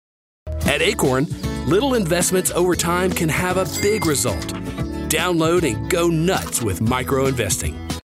North American General, North American Southern
Commercial
He operates from a professional home studio equipped with an MKH416 microphone and Logic Pro, ensuring broadcast-quality audio with fast turnaround times.